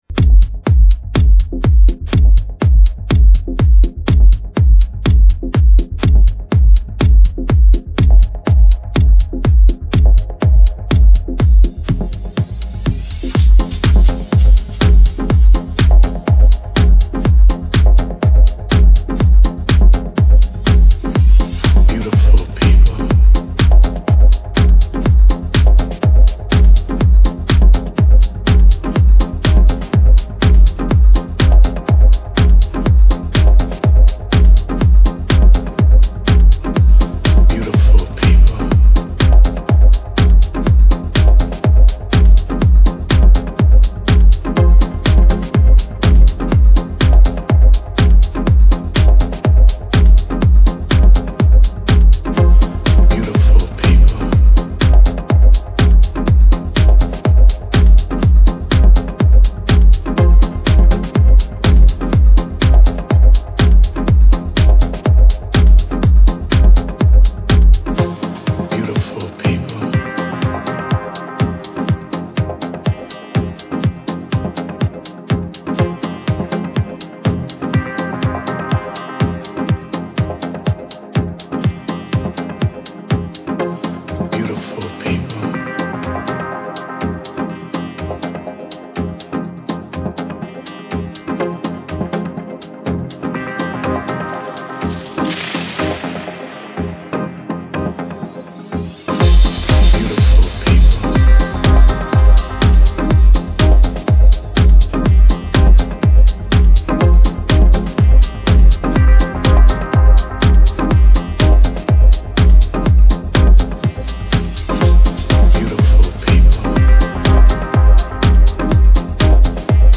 deep, moody synthesis and precise, punchy drums